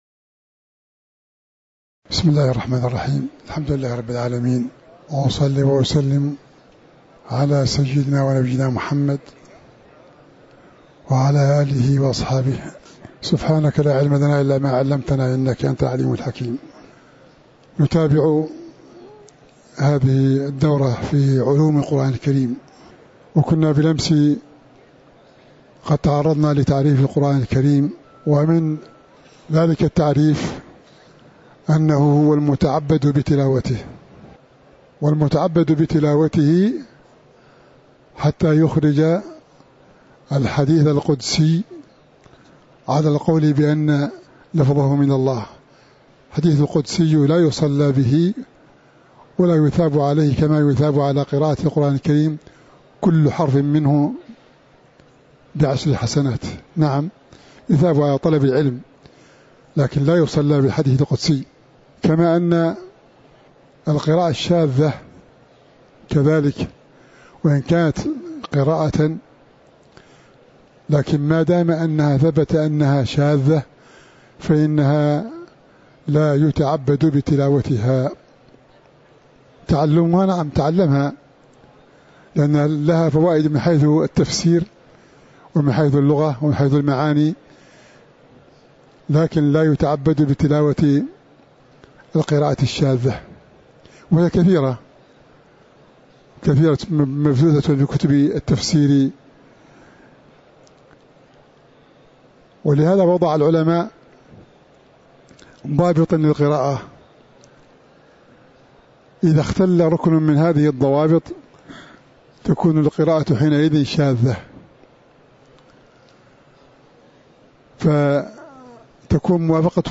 تاريخ النشر ١٢ محرم ١٤٤٥ هـ المكان: المسجد النبوي الشيخ